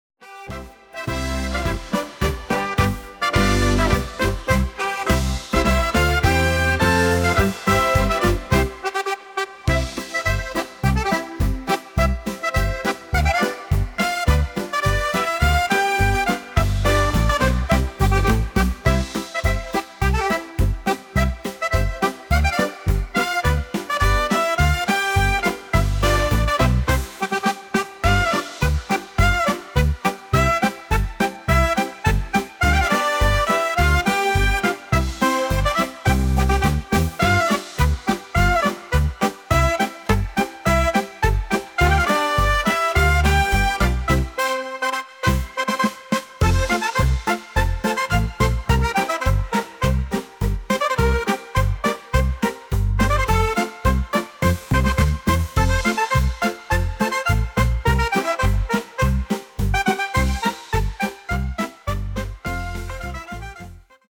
Auf geht's, jetzt wird getanzt
Musik